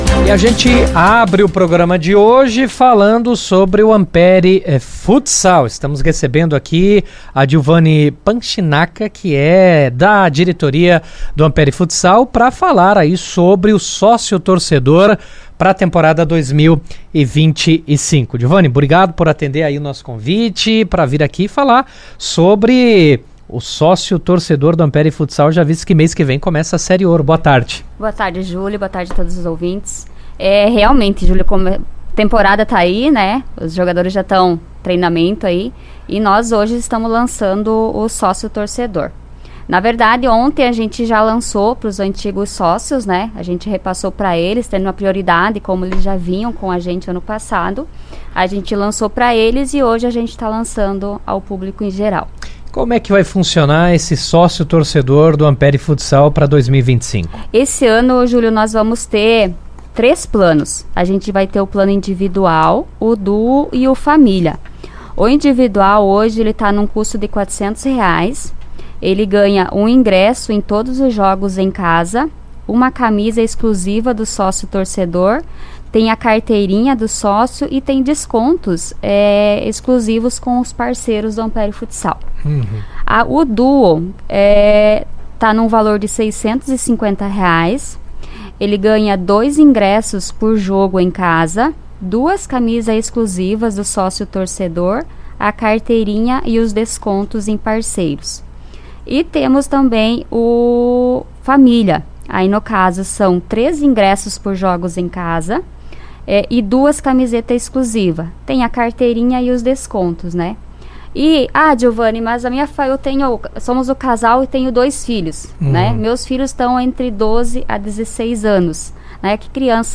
Em entrevista ao Jornal RA 2ª Edição desta terça-feira, 4 de fevereiro